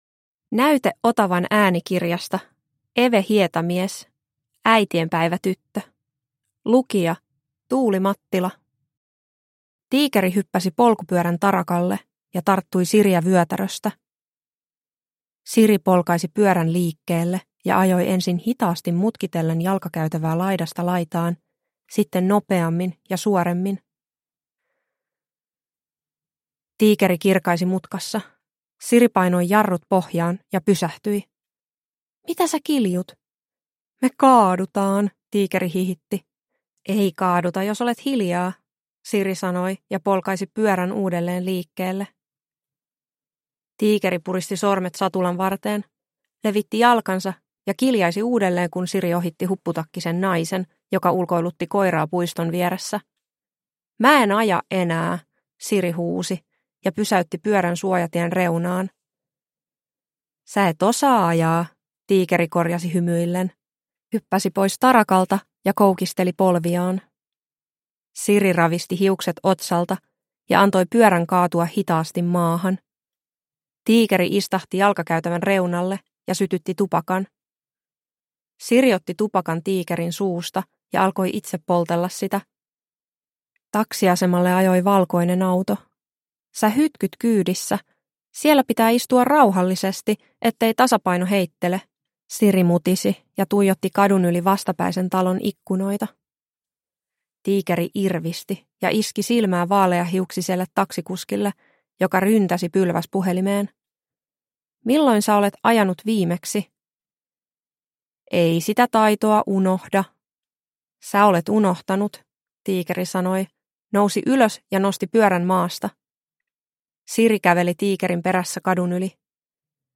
Äitienpäivätyttö – Ljudbok – Laddas ner